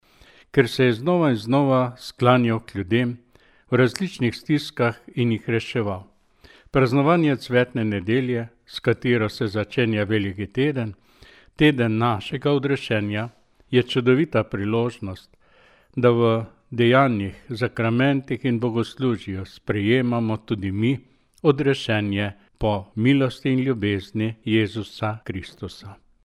Duhovnik